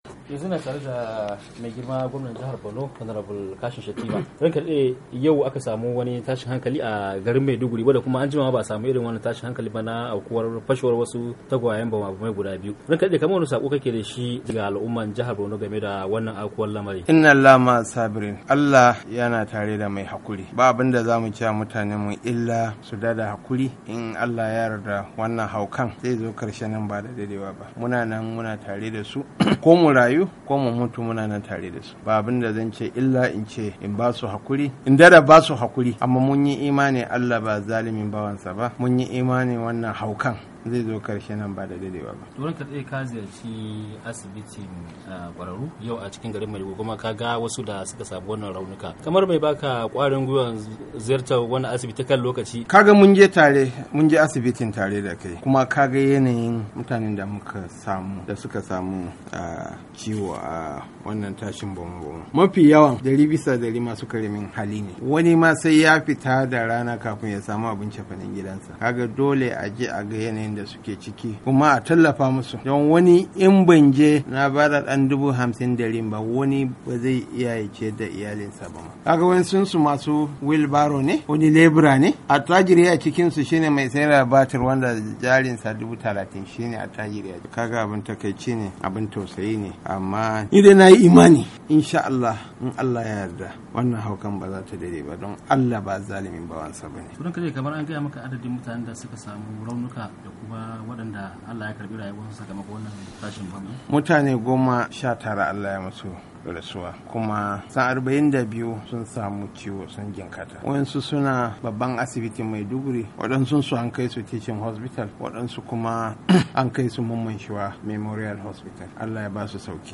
A hirarsa da waklinmu a Maiduguri